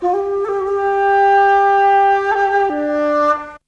ZG FLUTE 5.wav